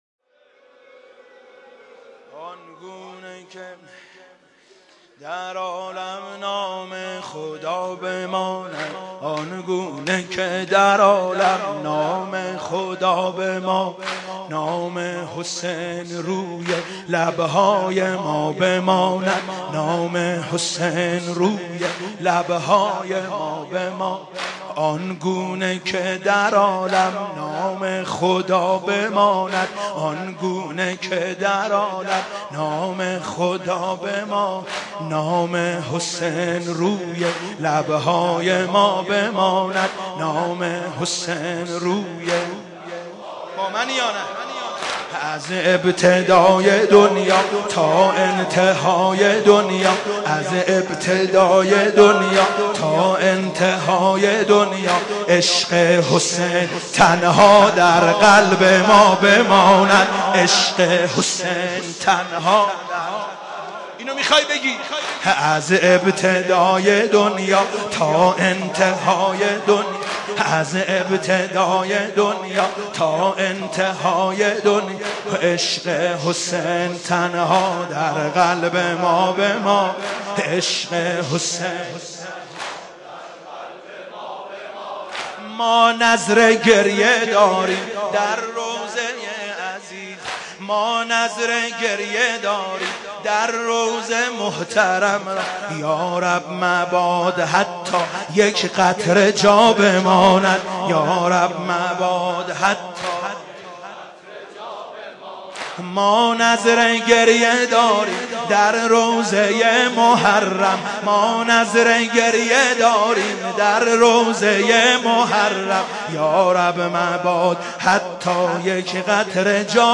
مداحی جدید حاج سید مهدی میرداماد شب سوم محرم97 تهران مسجد امیر
نوحه محرم